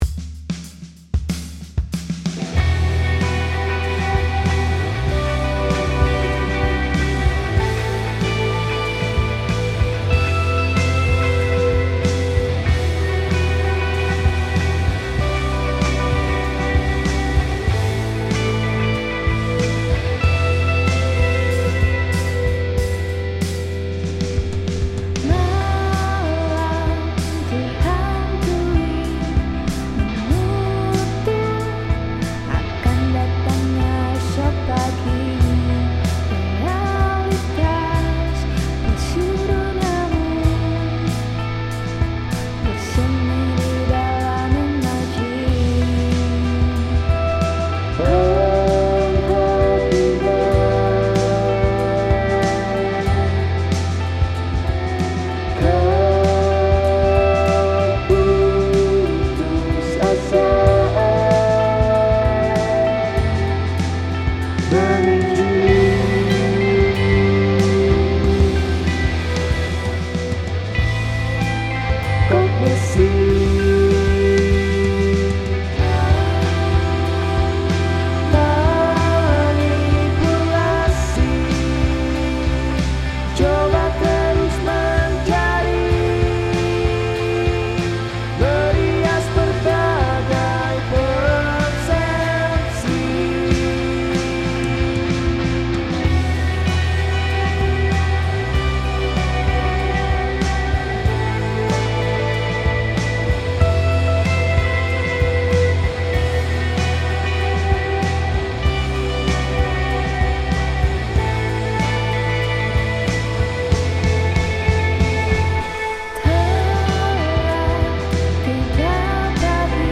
Malang Alternative